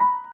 piano_last26.ogg